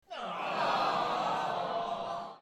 disappointed.mp3